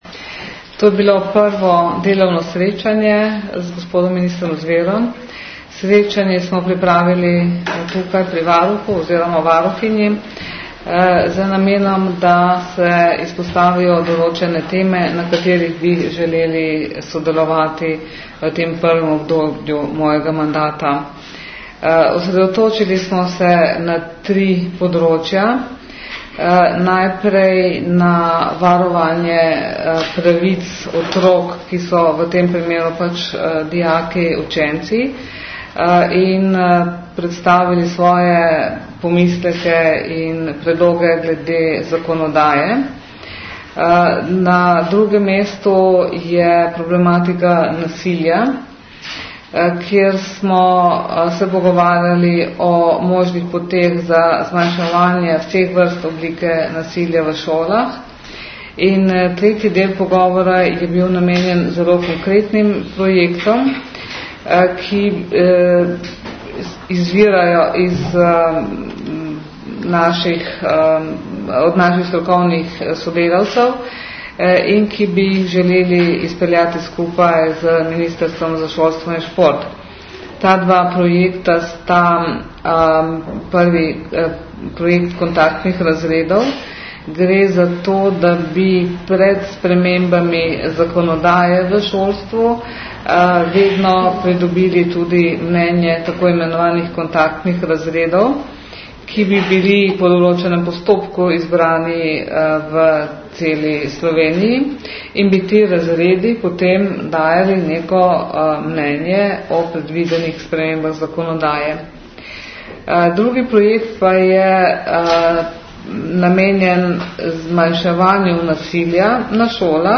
Varuhinja danes delovno z ministrom Zverom - zvočni posnetek skupne izjave